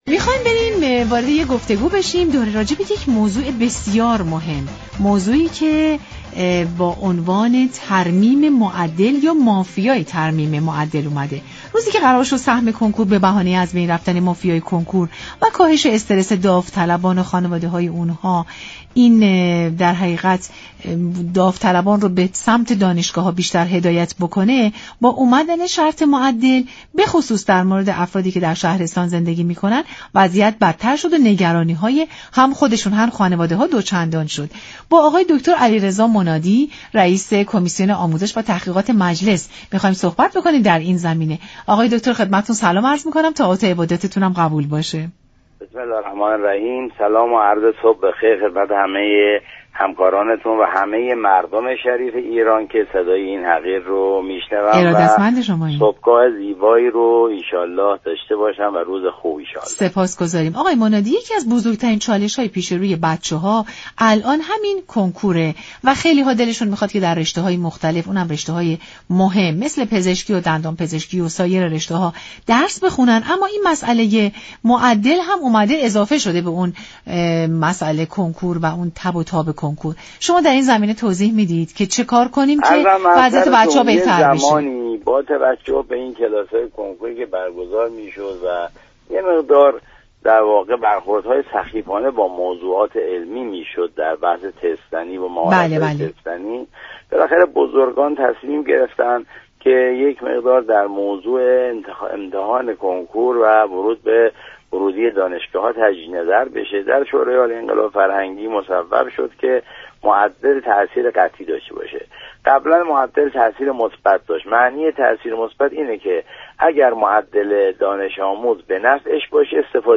رییس كمیسیون آموزش و تحقیقات مجلس در برنامه سلام‌صبح‌بخیر گفت: حذف معدل یازدهم، تاثیر معدل پایه دوازدهم را به 60درصد می‌رساند